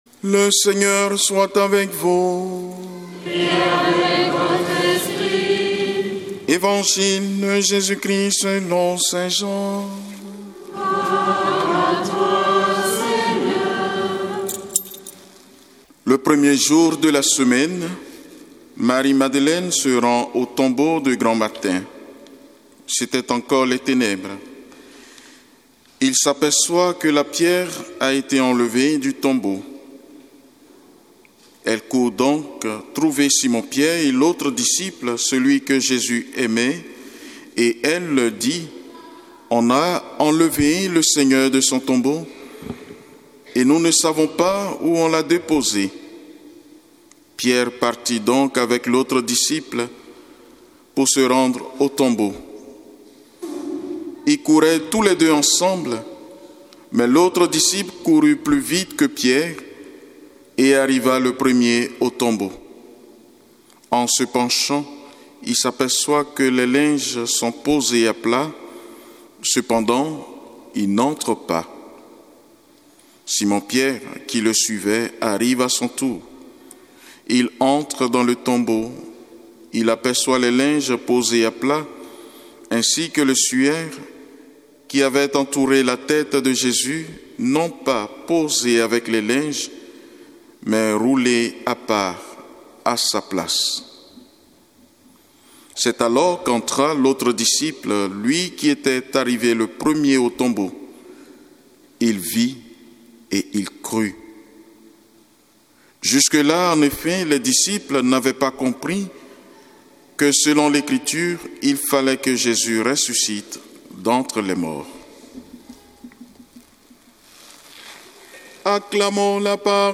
Évangile de Jésus Christ selon saint Jean avec l'homélie